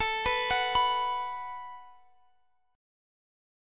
Listen to B5/A strummed